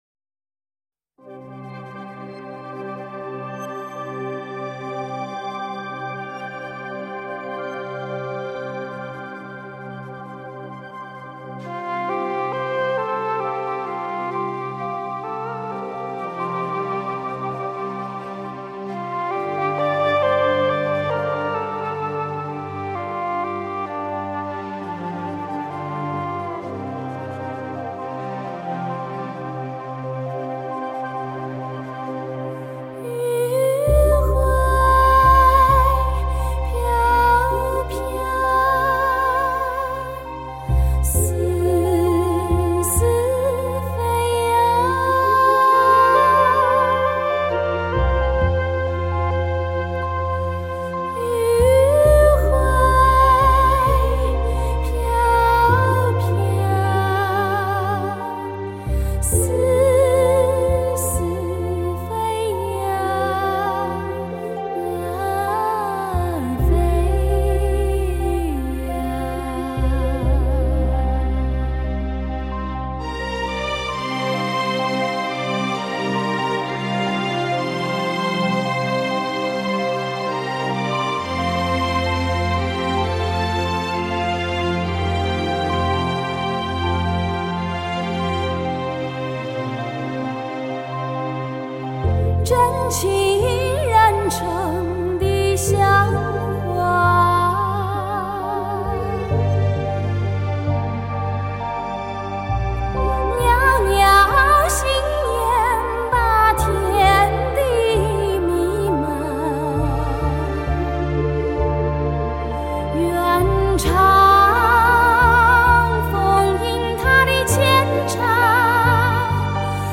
Mazu (Children's Voice)